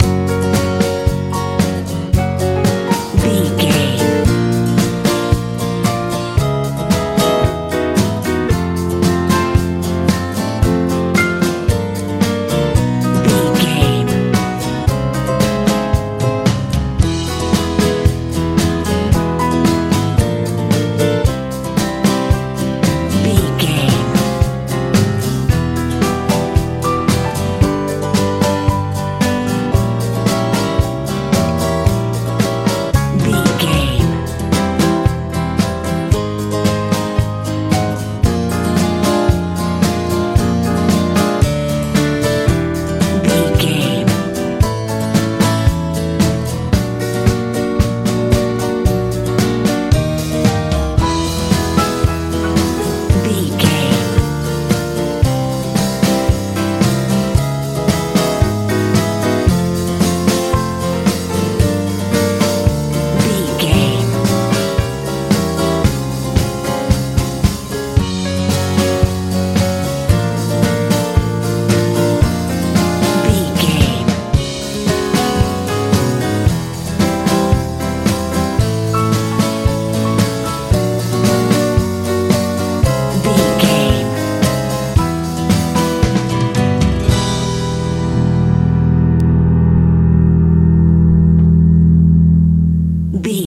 lite pop fell
Ionian/Major
F♯
bright
piano
acoustic guitar
bass guitar
drums
smooth
lively